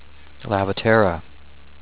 Pronunciation:
lav-ah-tear-ah